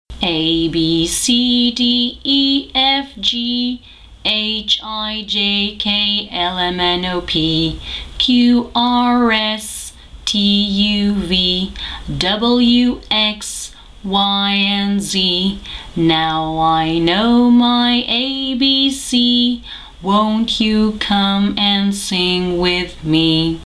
la chanson de l'alphabet phonétique et la prononciation de l'alphabet phonétique.
alphabetsong.mp3